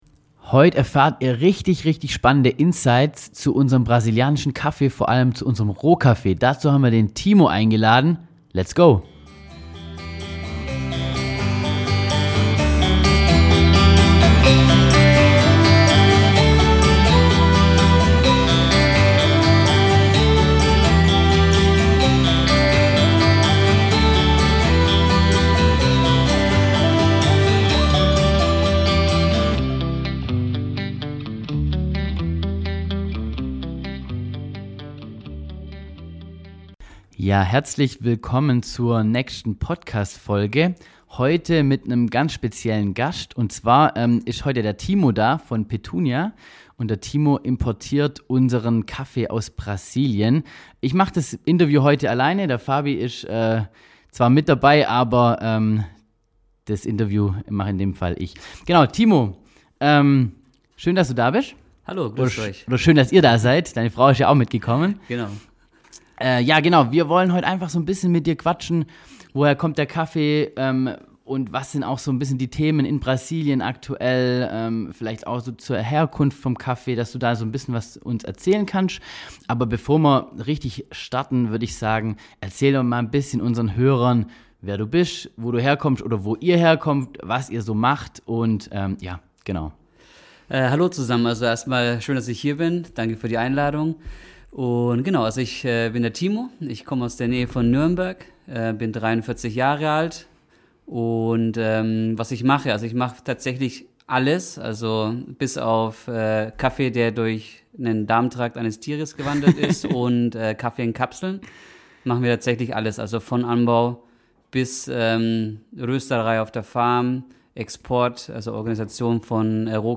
Kaffeeanbau in Brasilien - Interview